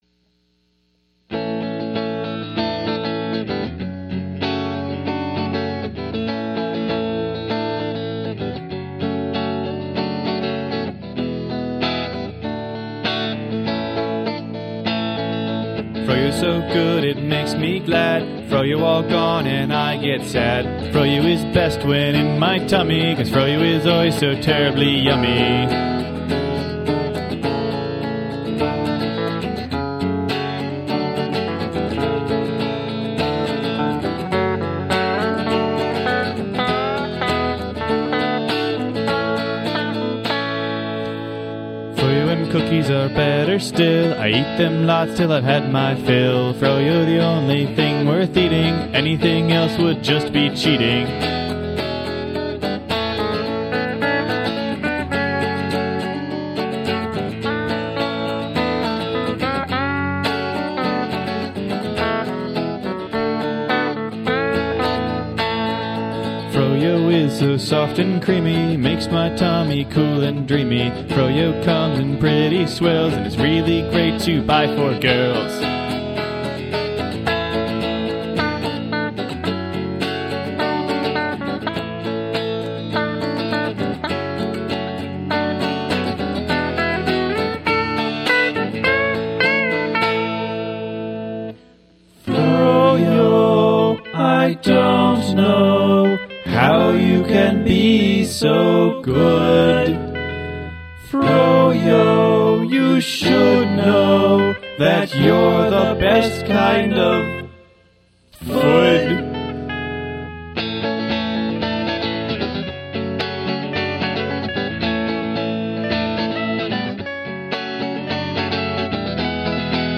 goofy song